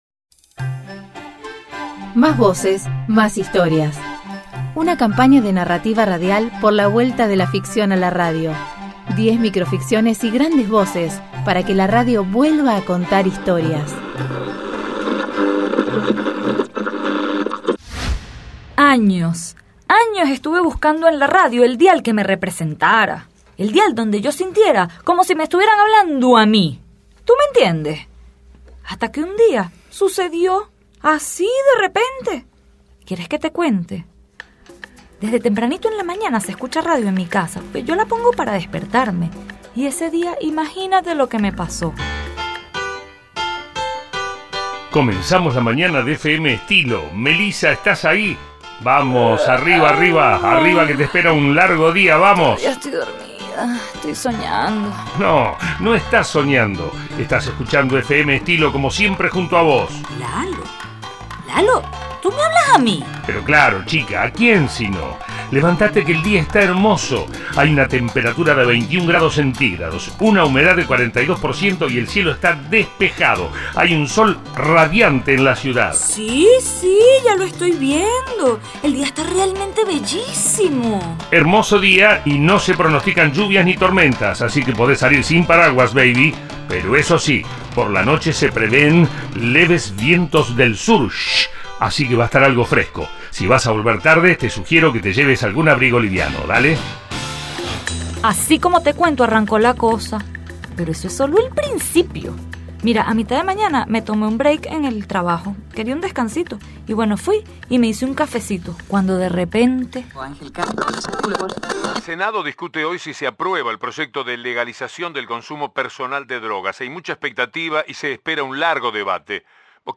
Los radioteatros están disponibles para su descarga individual (debajo) o grupal AQUÍ.